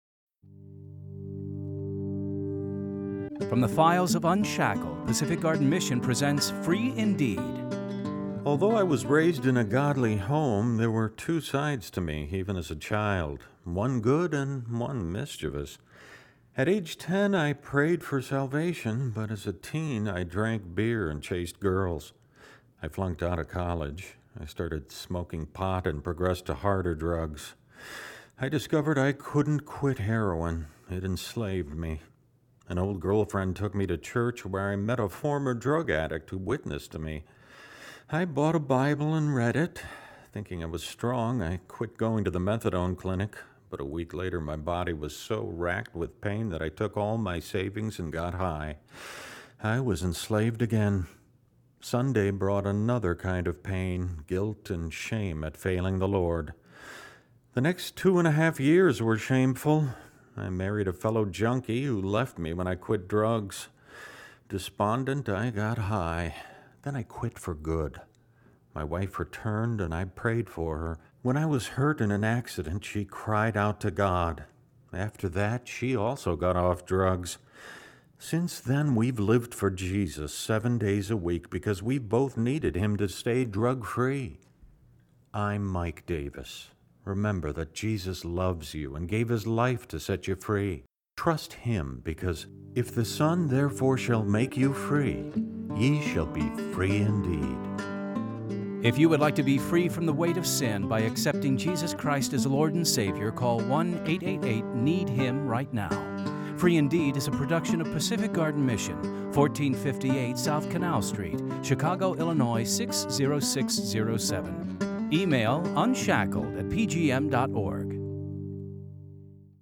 Two Minute Testimonies of True Life Stories
Free Indeed! stories are created from the files of UNSHACKLED! Radio Dramas, and produced by Pacific Garden Mission.